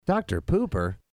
Tags: humor funny sound effects sound bites radio